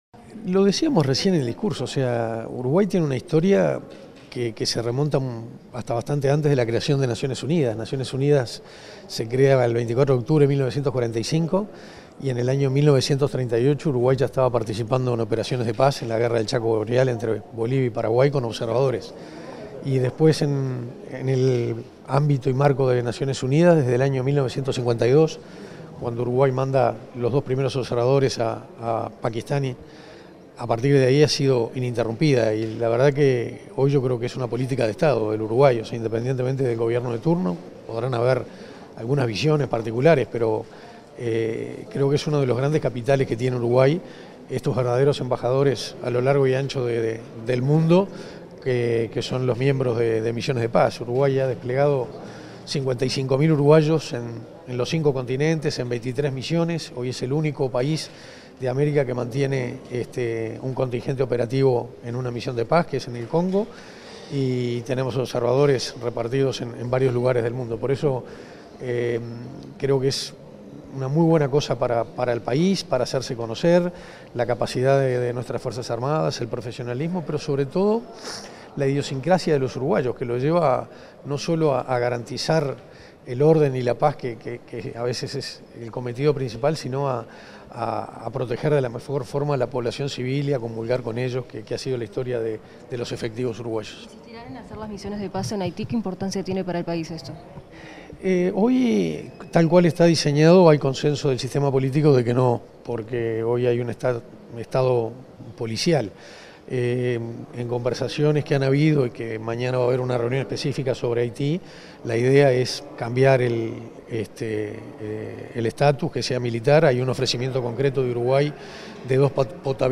Declaraciones del ministro de Defensa, Armando Castaingdebat
Declaraciones del ministro de Defensa, Armando Castaingdebat 10/12/2024 Compartir Facebook X Copiar enlace WhatsApp LinkedIn Tras la Reunión Preparatoria Ministerial de las Naciones Unidas sobre Mantenimiento de la Paz, este 10 de diciembre, el ministro de Defensa Nacional, Armando Castaingdebat, dialogó con los medios informativos presentes.